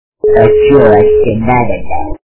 При прослушивании Злой Масяня - А че вооще надо-то качество понижено и присутствуют гудки.